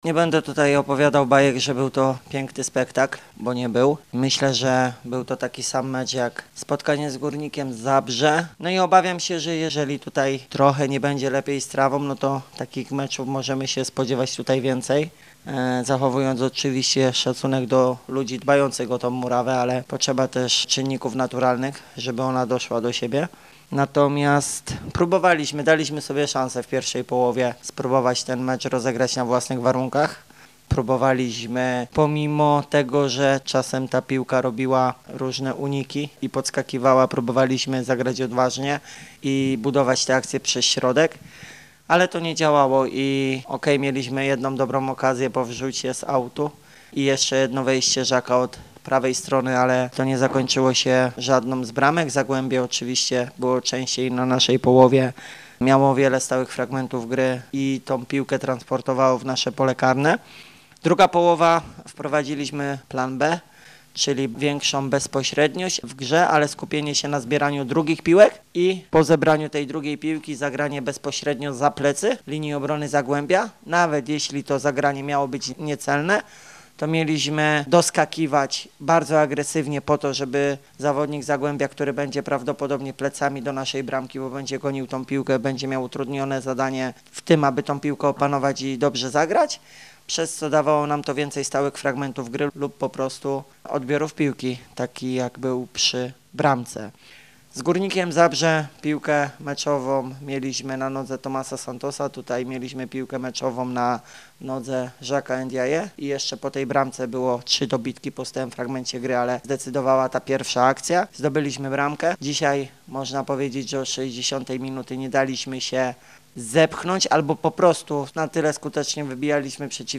mówił na pomeczowej konferencji prasowej